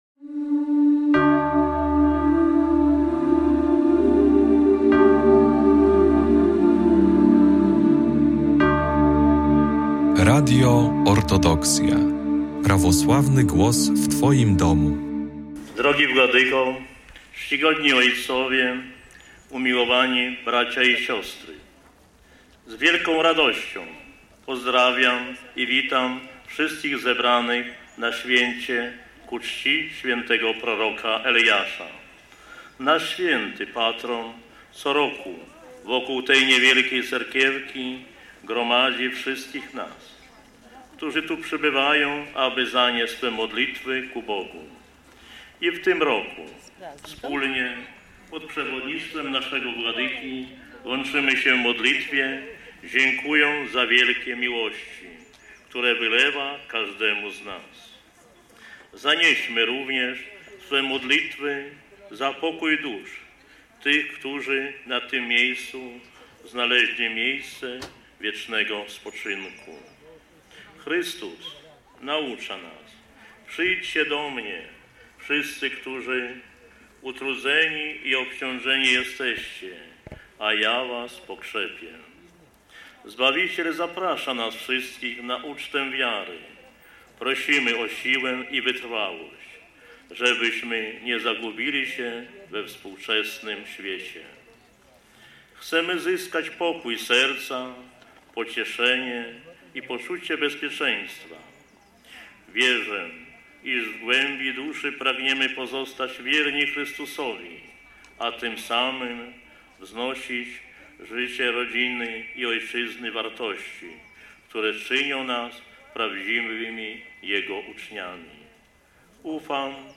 W dniu święta Boskiej Liturgii przewodniczył Jego Ekscelencja Najprzewielebniejszy Andrzej Biskup Supraski.